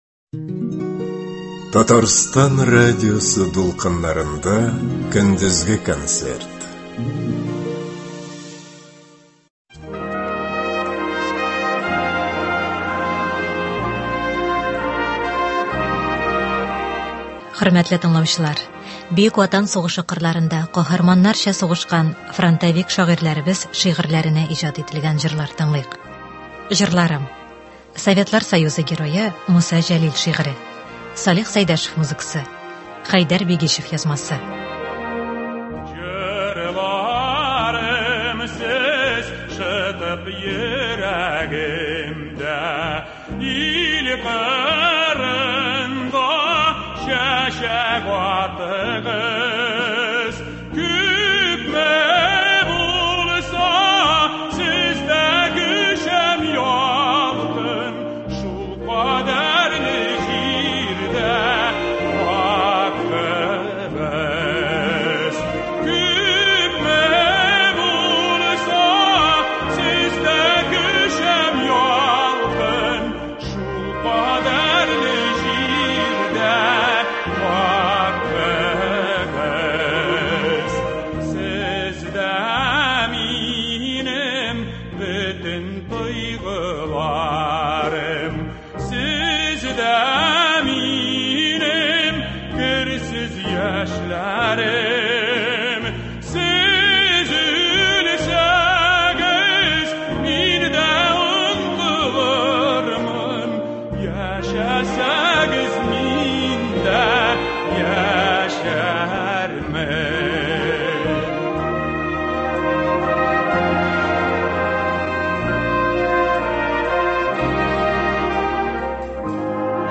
Фронтовик шагыйрьләр шигырьләренә җырлар.
Татарстан сәнгать осталары концерты.